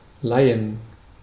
Ääntäminen
US : IPA : [ˈbɑroʊ]